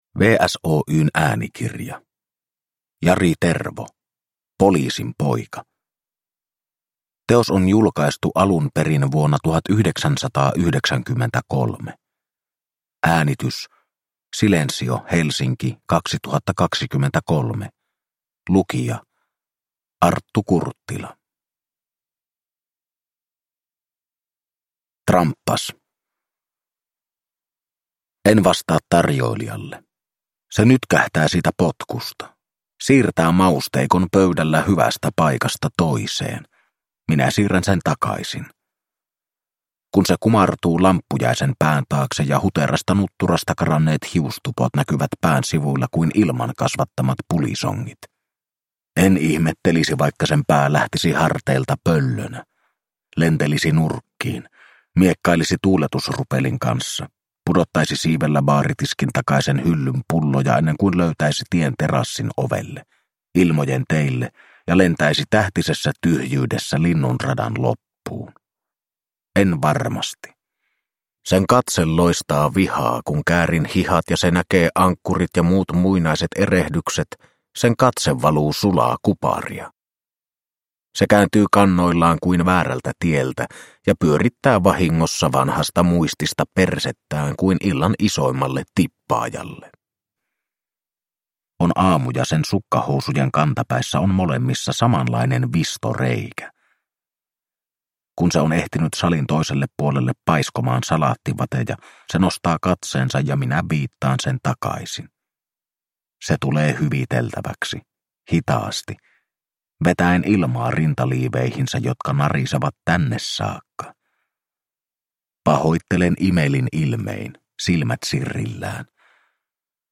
Poliisin poika – Ljudbok – Laddas ner